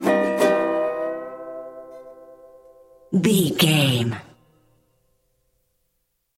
Ionian/Major
acoustic guitar
banjo
percussion
ukulele
slack key guitar